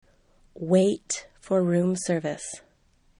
wait (for room service)  wet